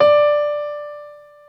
55p-pno26-D4.wav